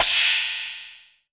Index of /90_sSampleCDs/AKAI S6000 CD-ROM - Volume 3/Crash_Cymbal1/FX_CYMBAL
AX+MIX CYM S.WAV